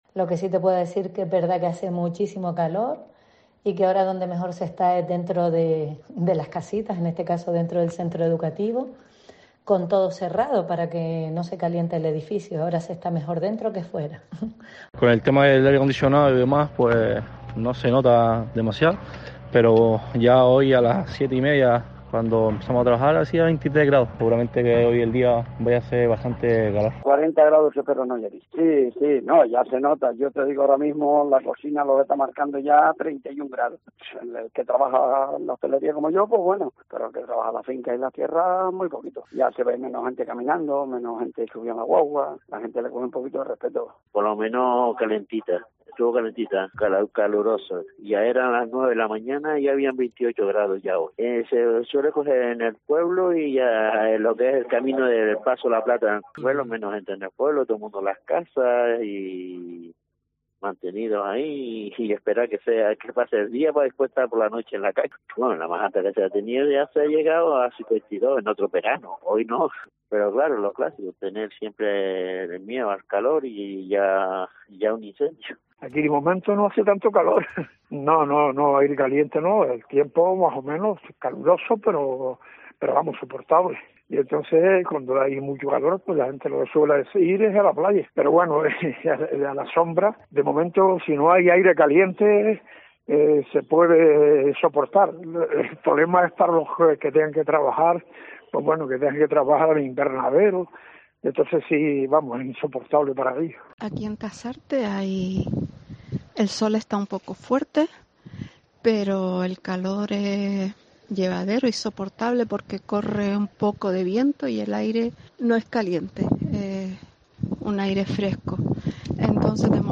Vecinos de Tejeda, Tunte, La Aldea y Tasarte nos cuentan cómo están viviendo este episodio de calor